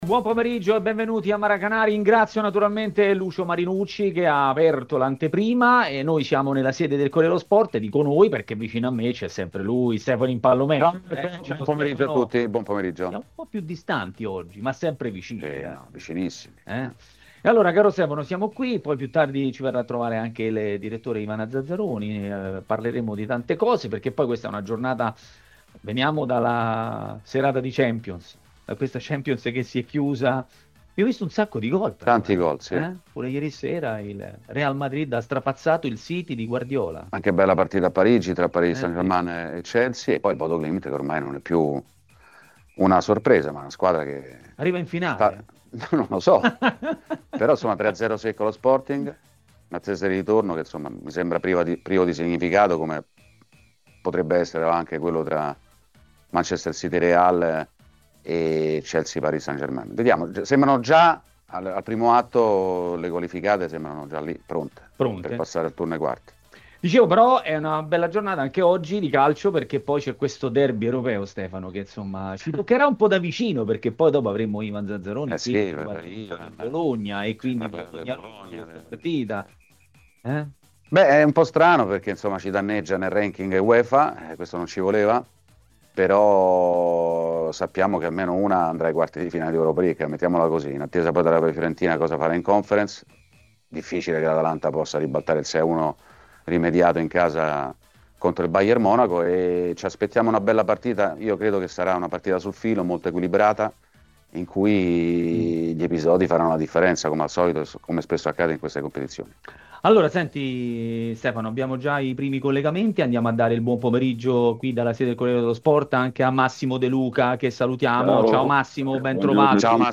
Il giornalista Italo Cucci è stato ospite di Maracanà, nel pomeriggio di TMW Radio.